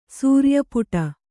♪ sūrya puṭa